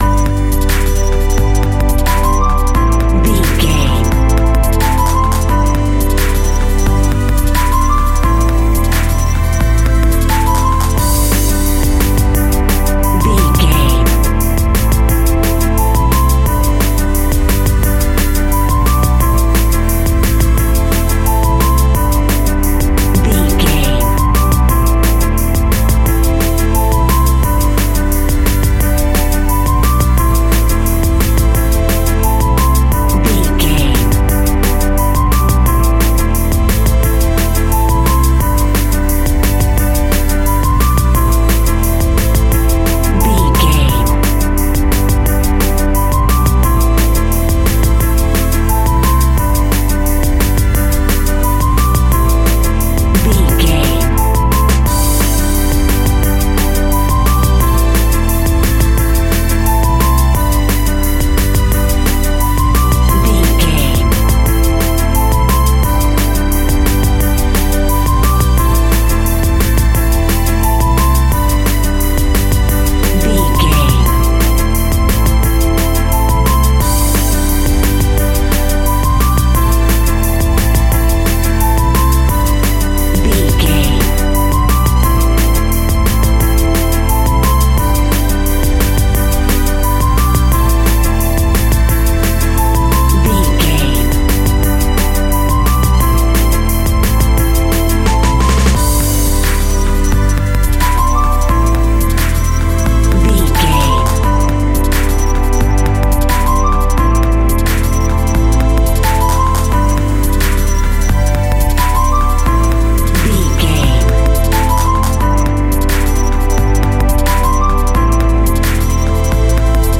Bass and Drums.
Aeolian/Minor
Fast
futuristic
hypnotic
industrial
dreamy
frantic
drum machine
synthesiser
piano
Drum and bass
break beat
sub bass
synth lead
synth bass